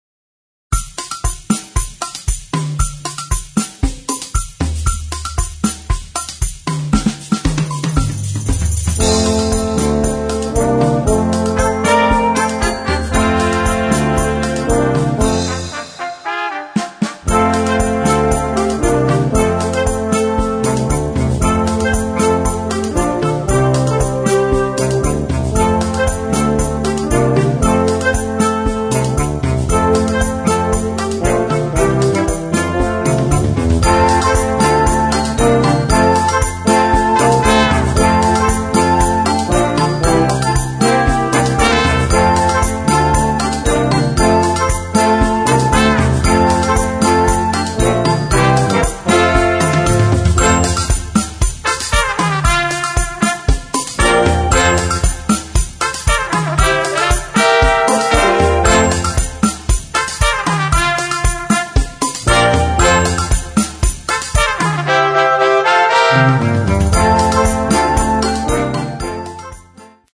Noten für Jugendblasorchester, oder Brass Band.